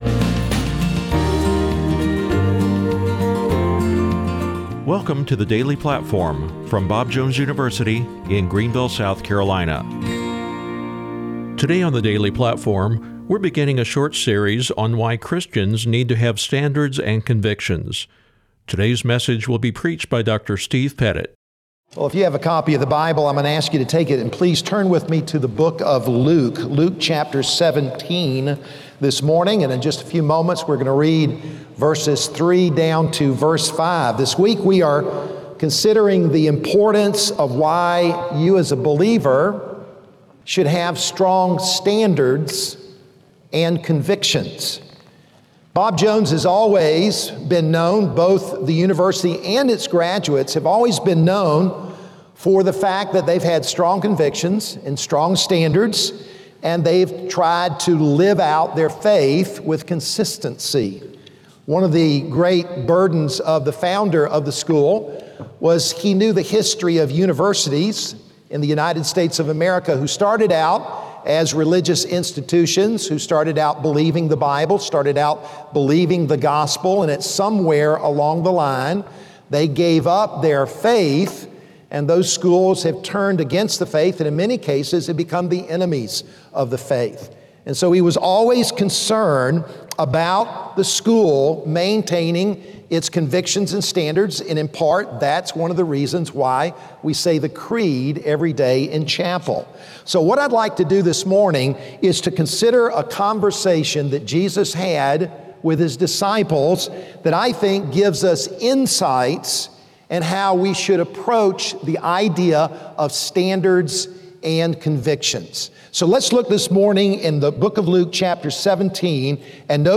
Today is the first message of a three-part series on Standards and Convictions